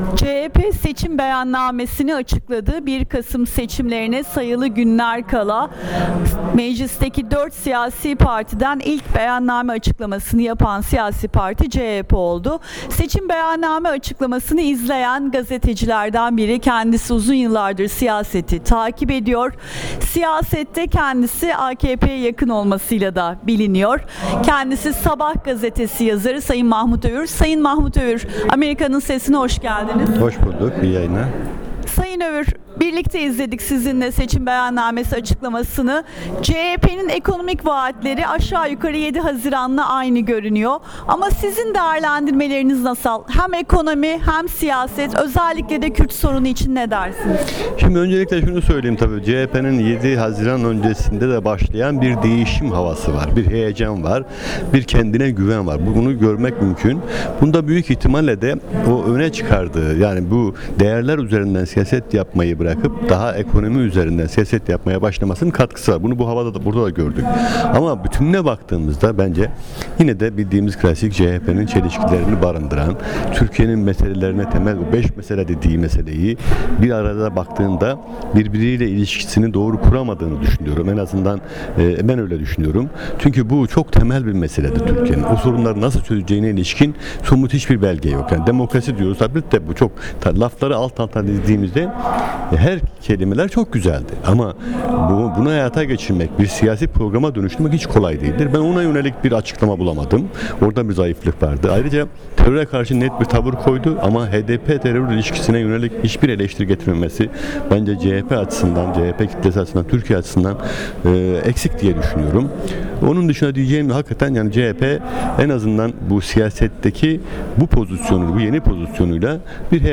söyleşi